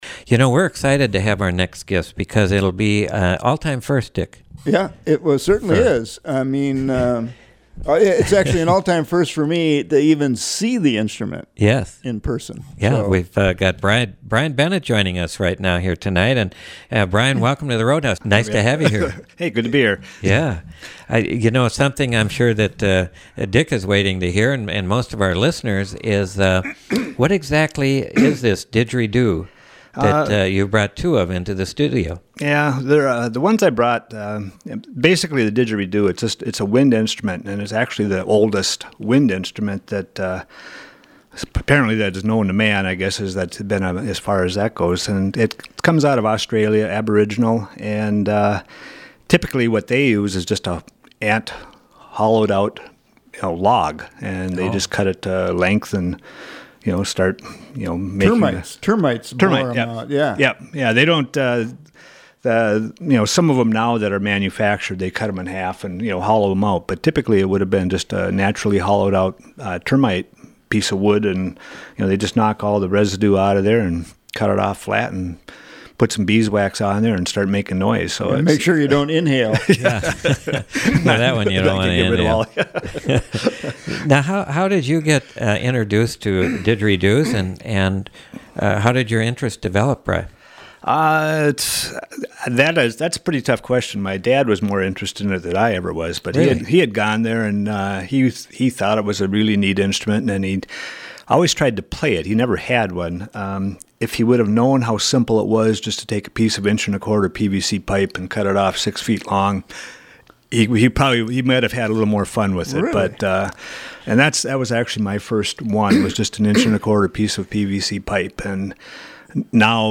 As far as we know, it's a didgeridoo debut on The Roadhouse--take a listen
The didgeridoo is a wind instrument that originated with indigenous Australians, still in widespread use both in Australia and all over the world.